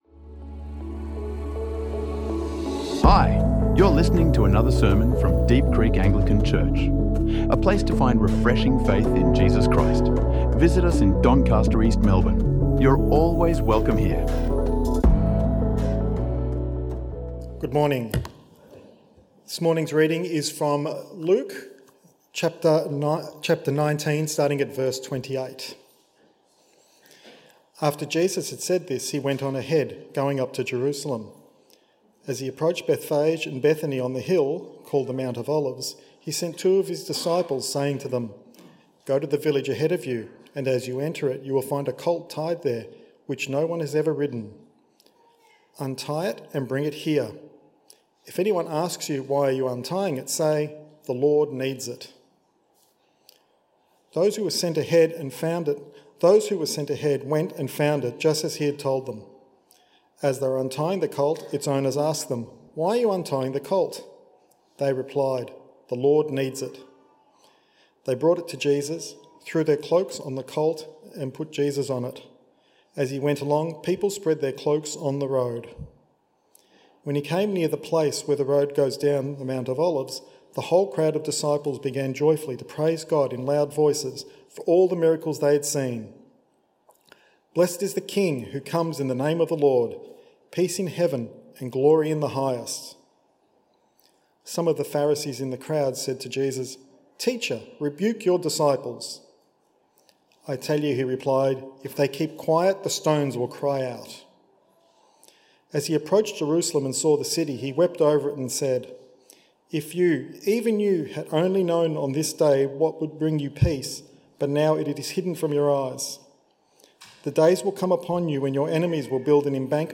Palm Sunday sermon exploring Jesus’ triumphal yet heart-breaking entry into Jerusalem as a humble King and weeping Savior longing for his people’s peace.